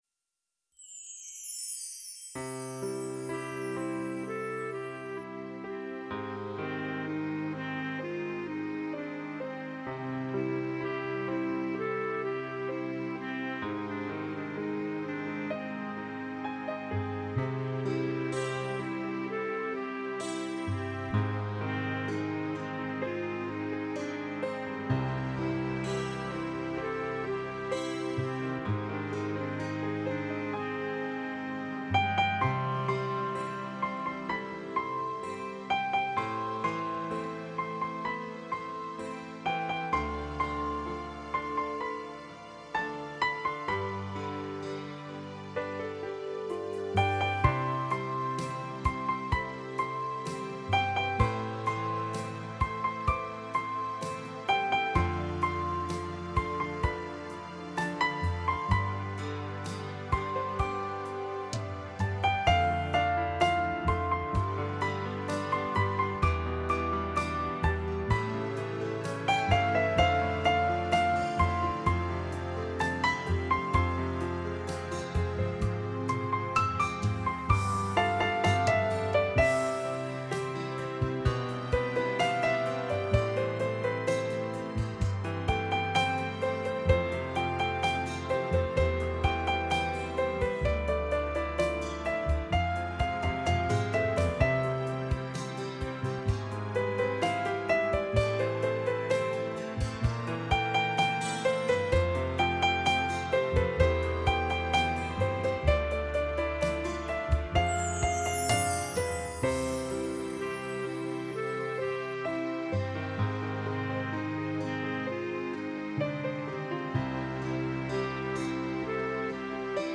天籁发烧盘，精选11首混声合唱好歌，
24bit/96khz的多轨数码录音，
保证了音色的清晰度和更宽阔的音域动态范围，
6个声道的数码输出，全面超越传统CD（2声道）的音效，